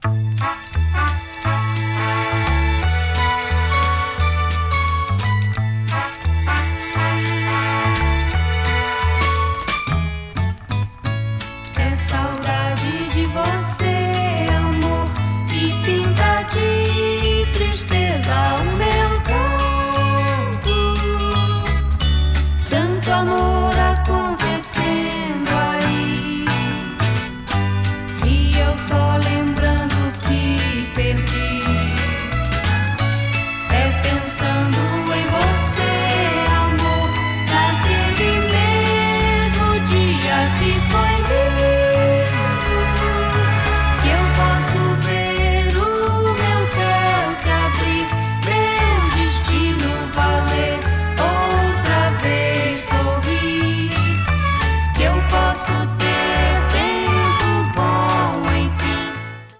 程よくグルーヴィーなナナナ・ブラジリアン・ソフト・ロックです！
70s LATIN / BRASIL 多幸感 詳細を表示する